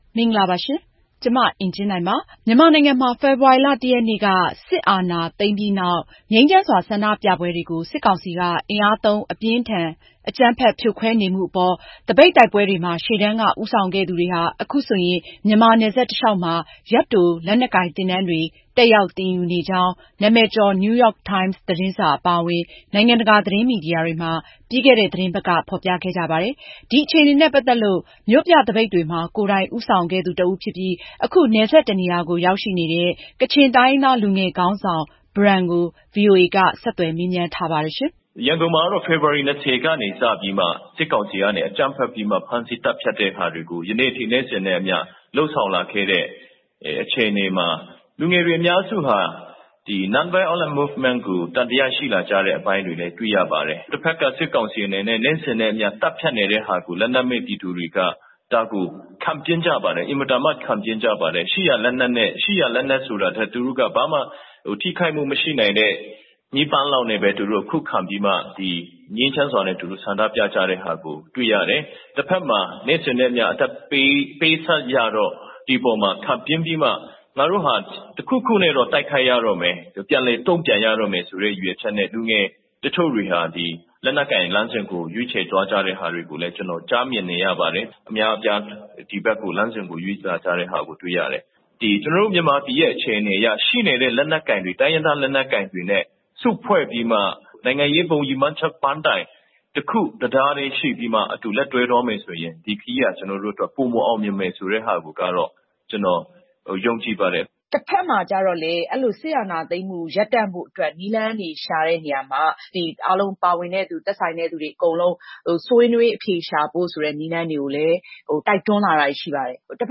လက်နက်ကိုင်တော်လှန်ရေးအပေါ် မျှော်လင့်ချက်ထားတဲ့ ကချင်လူငယ်တဦးနှင့် မေးမြန်းချက်